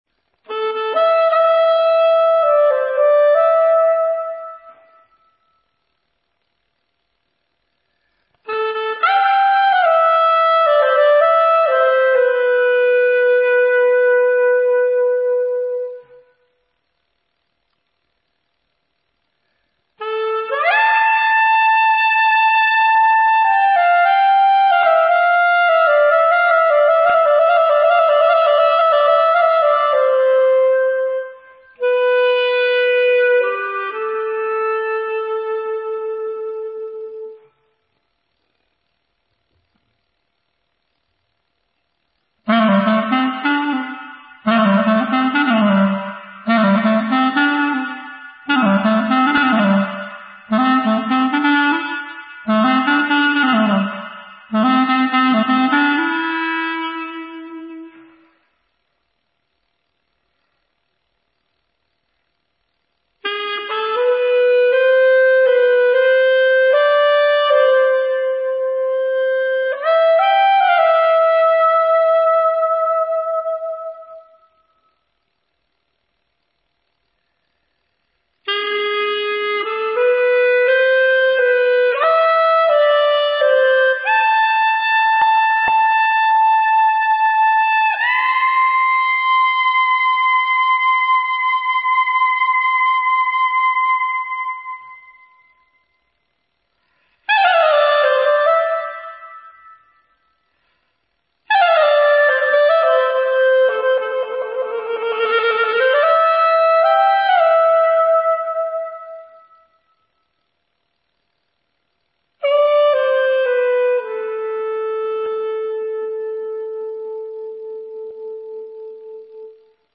标签： 120 bpm Dance Loops Woodwind Loops 3.72 MB wav Key : Unknown